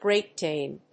アクセントGréat Dáne
音節Grèat Dáne